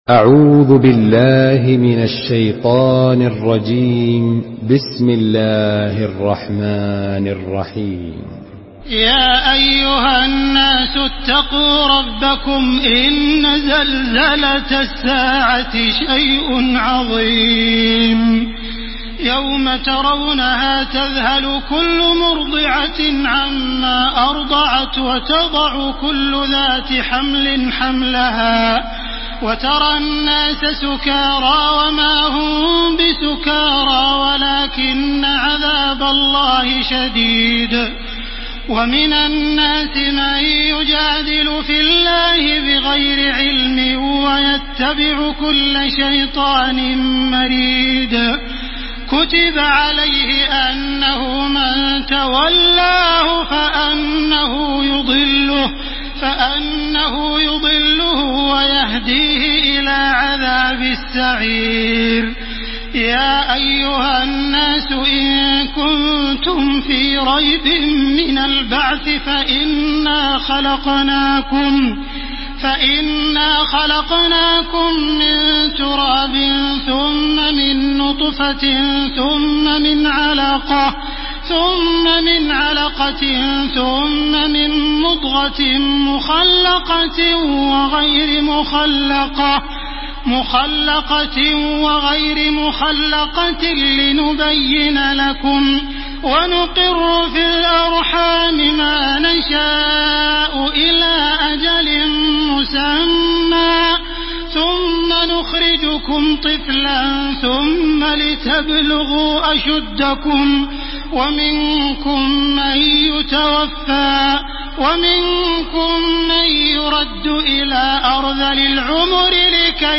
Surah Hac MP3 in the Voice of Makkah Taraweeh 1431 in Hafs Narration
Murattal